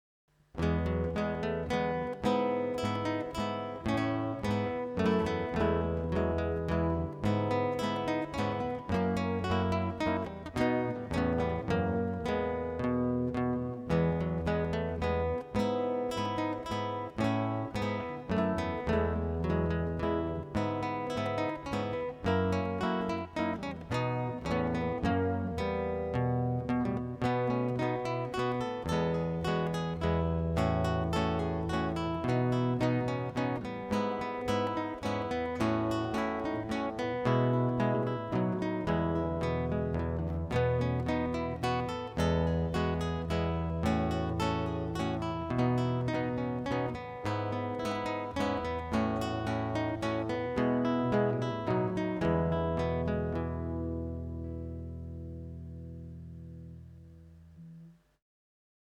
A Minuet in E major recorded as a double tracked duet by the Baroque composer Adam Falckenhagen.